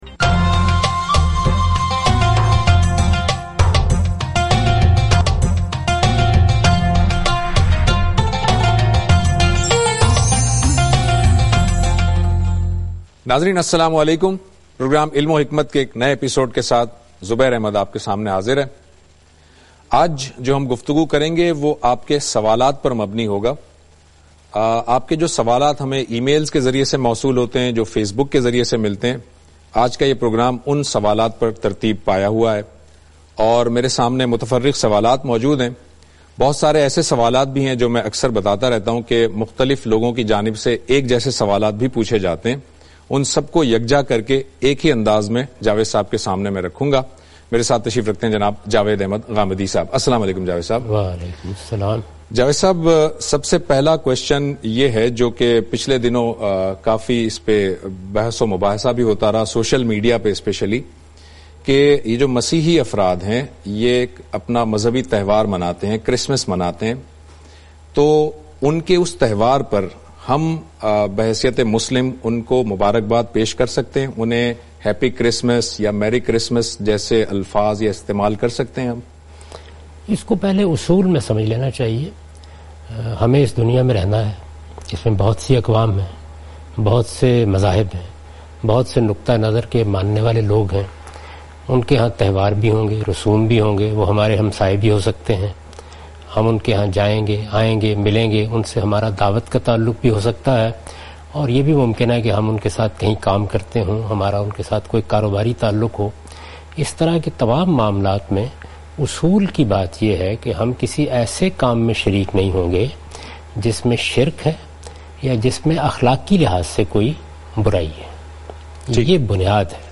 In this program Javed Ahmad Ghamidi answers miscellaneous questions.